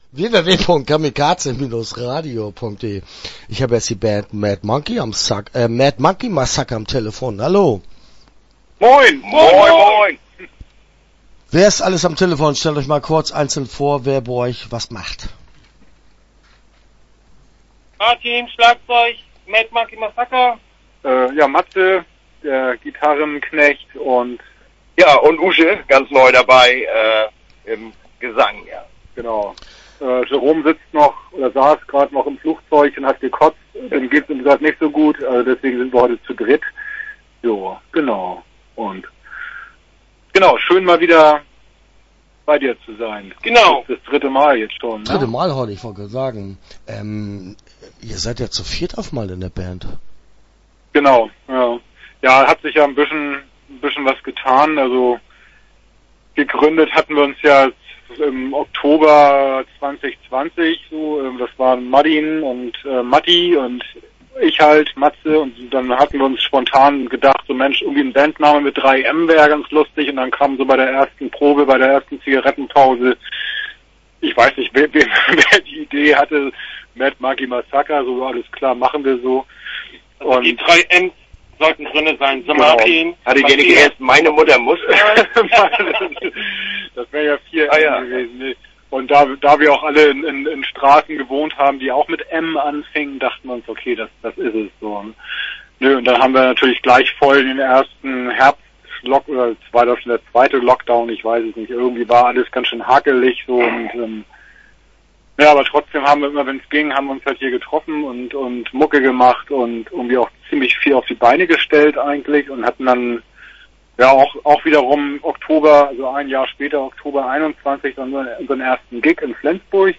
Mad Monkey Massacre - Interview Teil 1 (1) (13:45)